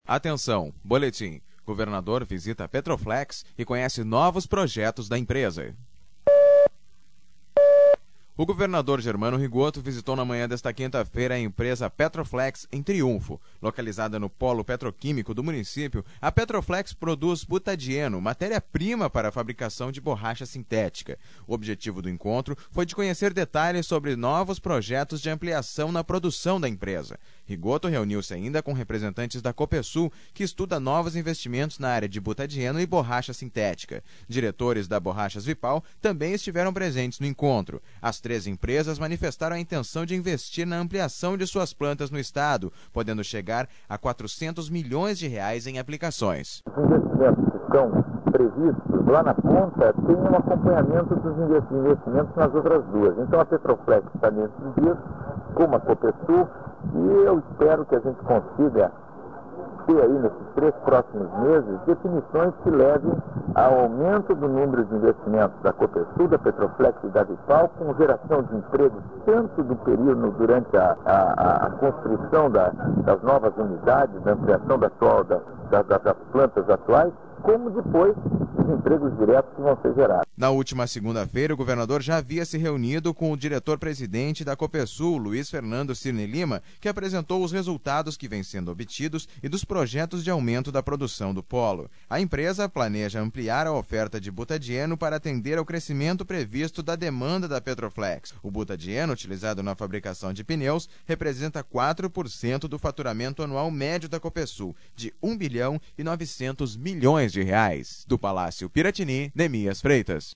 (sonora: governador Ge